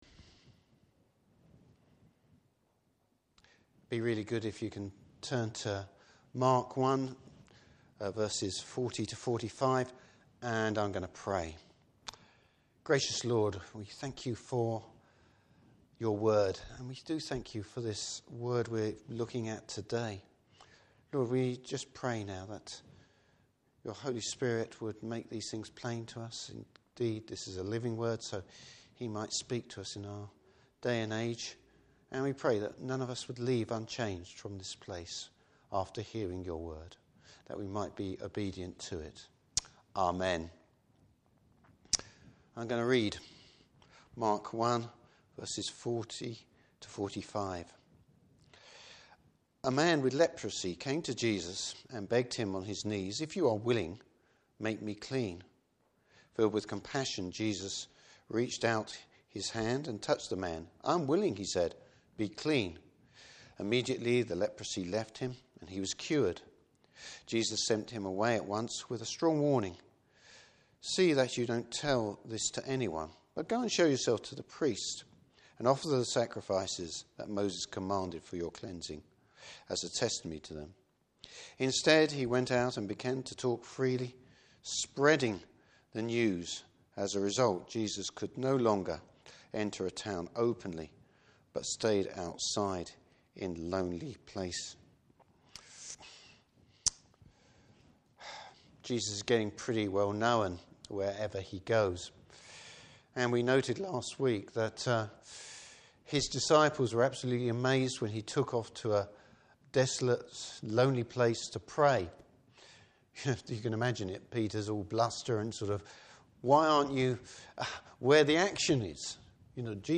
Service Type: Morning Service Jesus heals a leper.